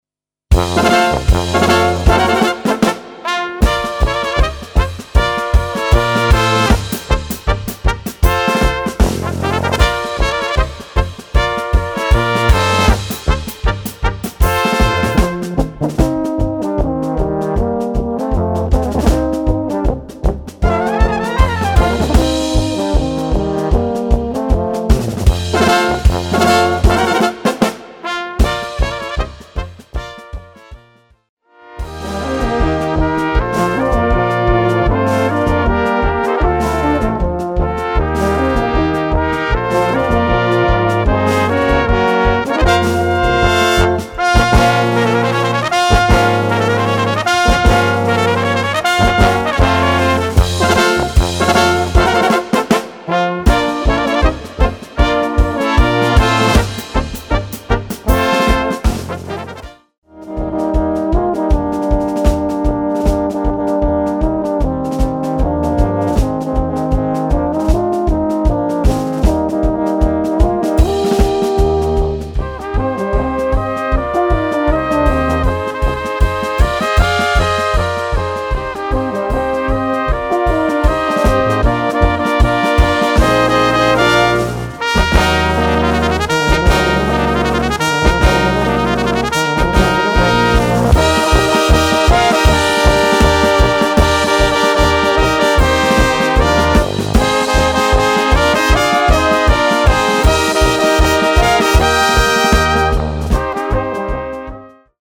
Gattung: Schnellpolka
Witzig und virtuos.